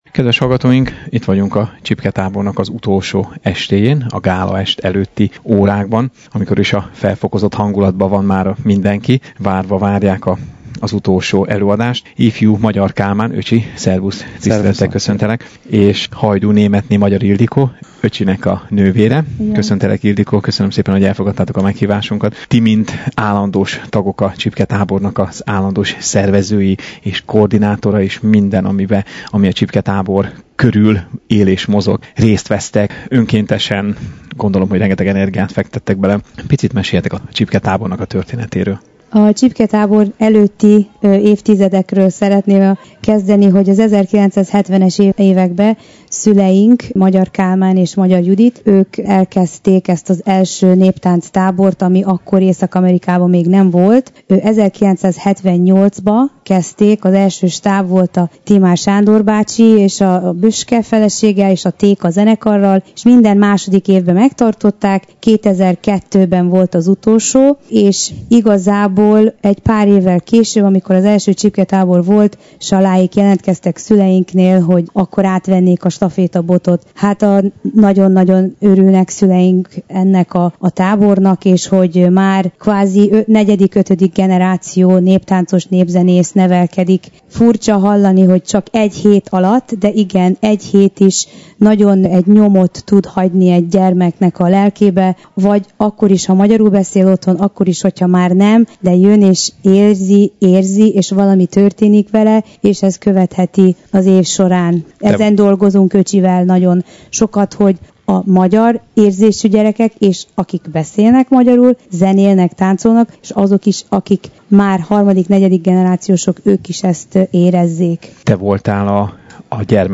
Kérésünket elfogadva leültünk egy rövid beszélgetésre velük.